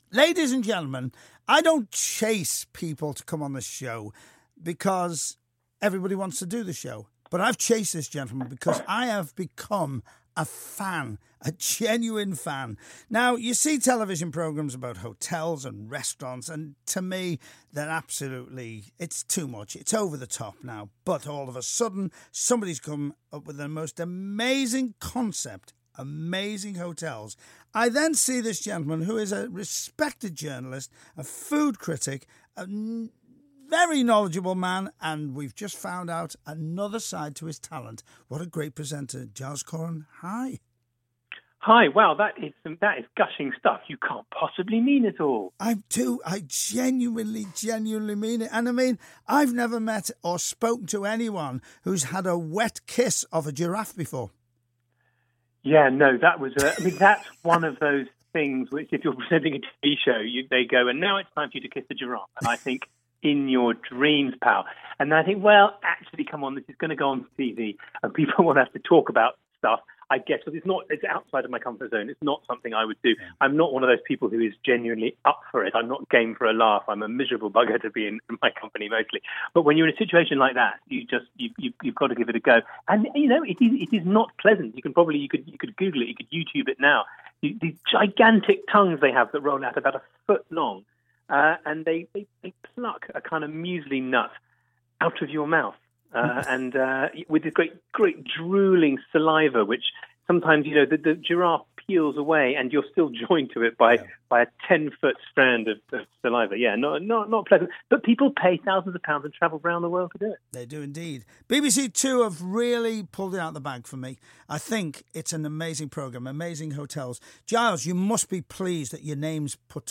Pete Price chats to Giles Coren about the TV Series 'Amazing Hotels - Life beyond the lobby'